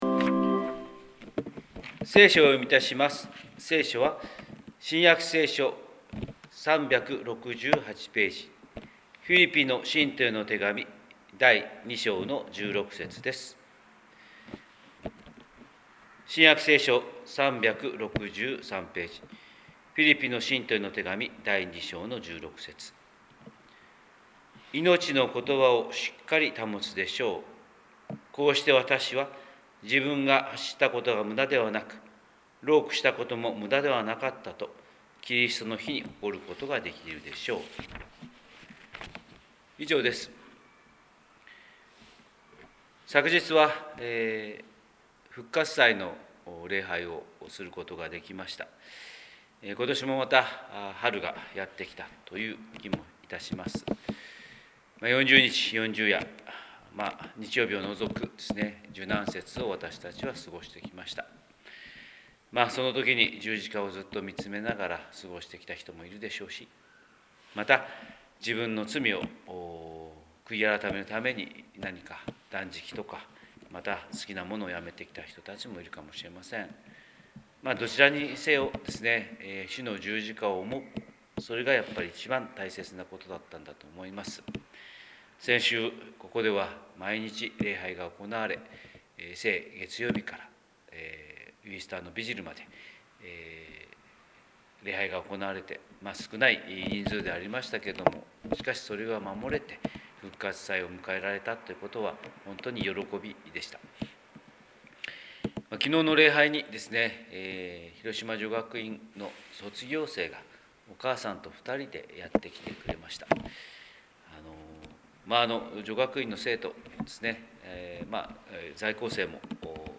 神様の色鉛筆（音声説教）: 広島教会朝礼拝250421